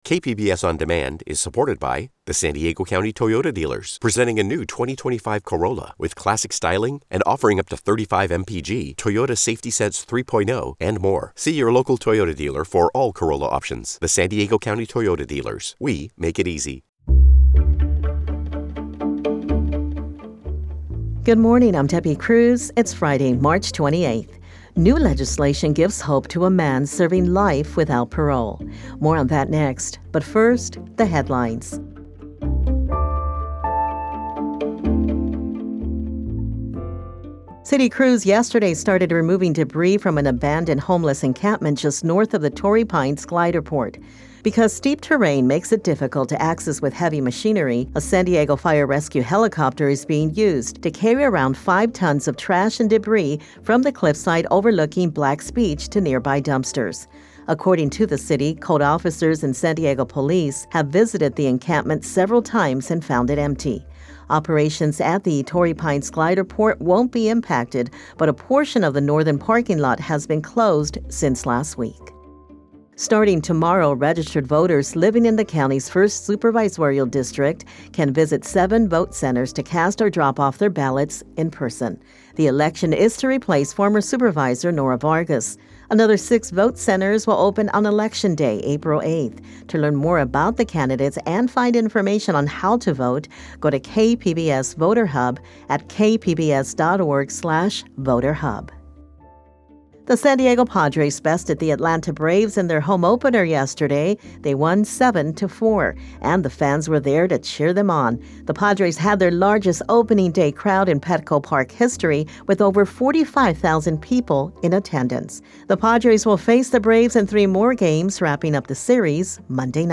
San Diego News Now 11 subscribers updated 23h ago Assinar Assinado Reproduzir Reproduzindo Compartilhar Marcar/Desmarcar tudo como reproduzido ...